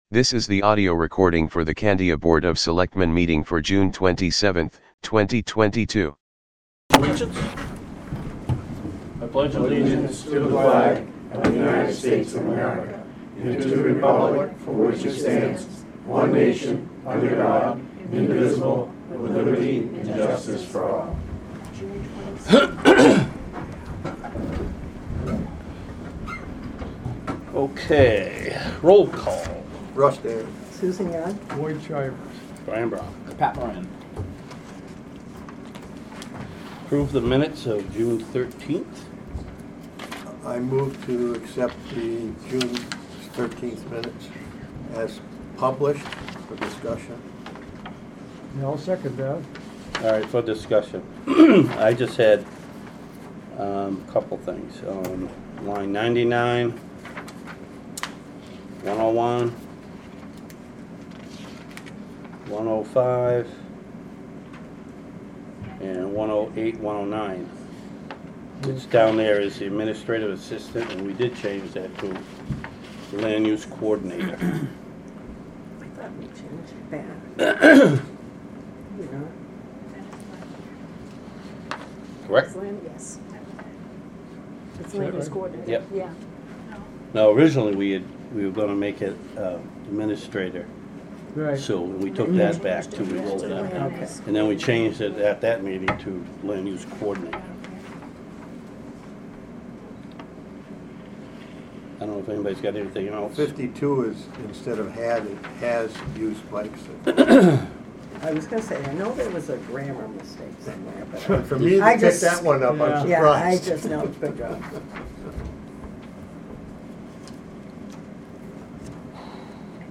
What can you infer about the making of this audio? Board of Selectmen Meeting